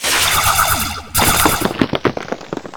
laser2.ogg